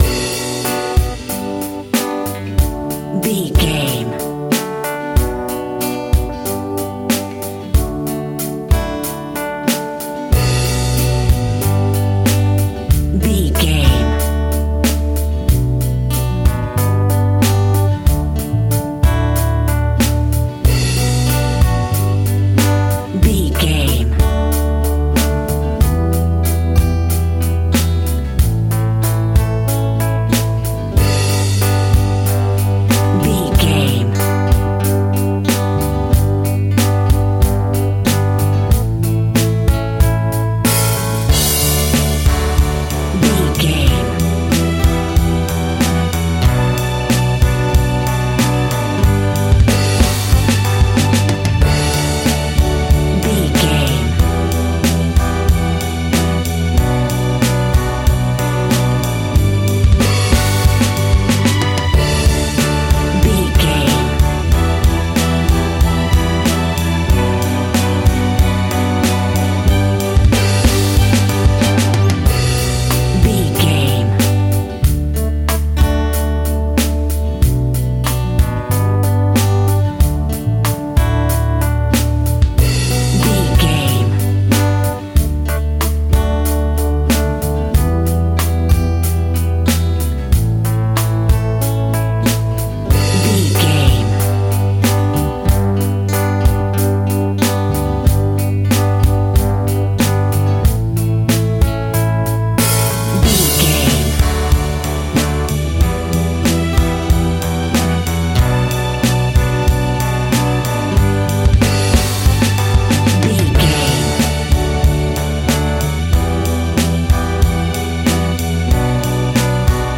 Ballad Indie Rock.
Ionian/Major
D
indie rock
pop rock
drums
bass guitar
electric guitar
piano
hammond organ